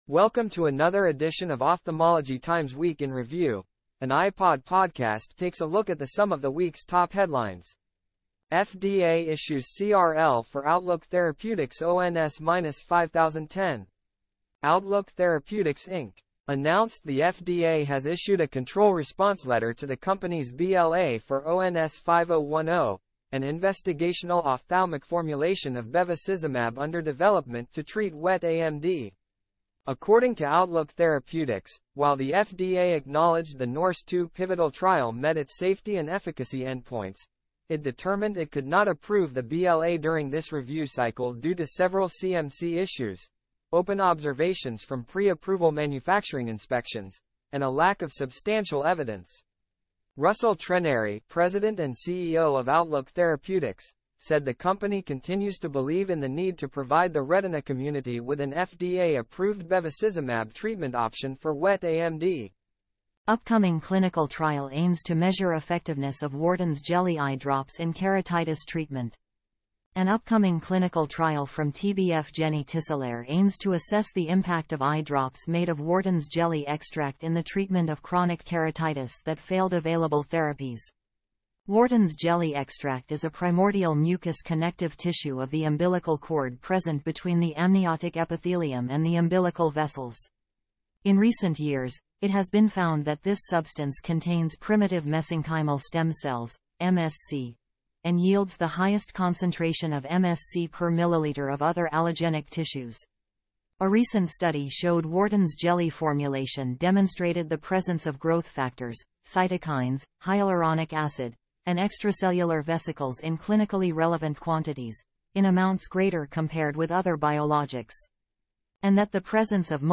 Editor's Note: This podcast was generated from Ophthalmology Times content using an AI platform.